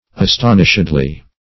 astonishedly - definition of astonishedly - synonyms, pronunciation, spelling from Free Dictionary Search Result for " astonishedly" : The Collaborative International Dictionary of English v.0.48: Astonishedly \As*ton"ish*ed*ly\, adv.